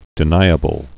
(dĭ-nīə-bəl)